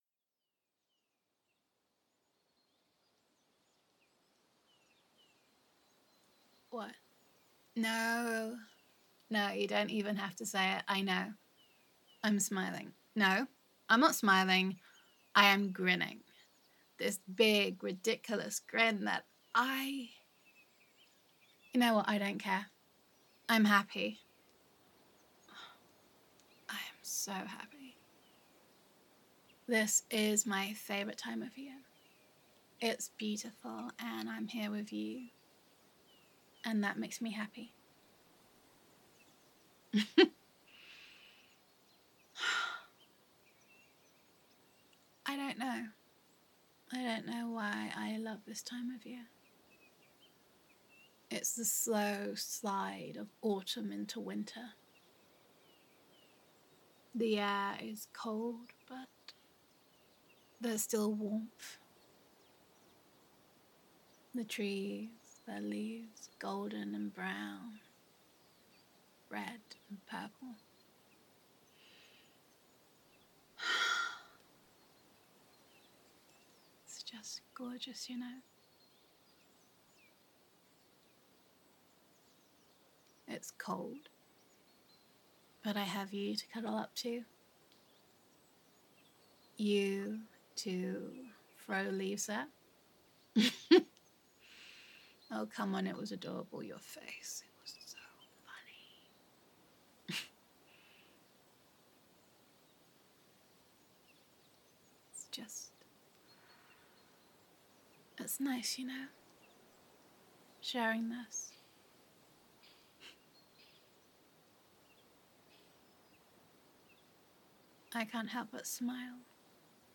[Romantic][Adorkable][Gender Neutral]